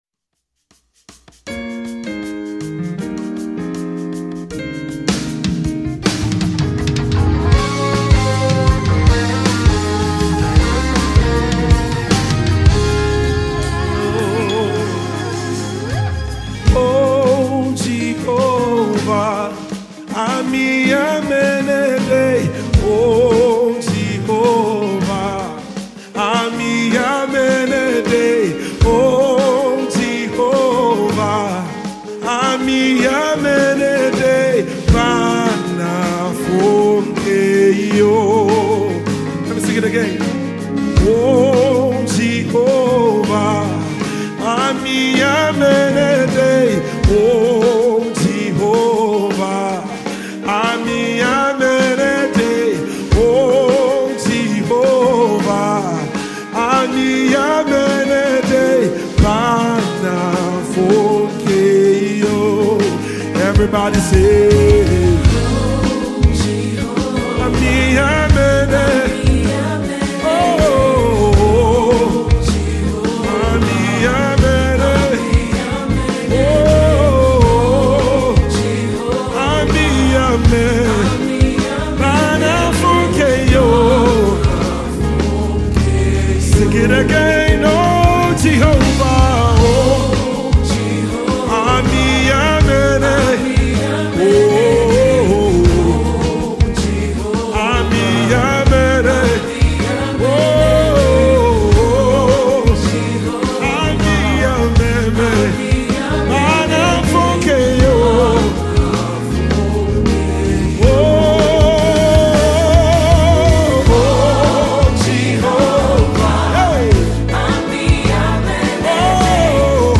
January 17, 2025 Publisher 01 Gospel 0
Ghanaian contemporary gospel singer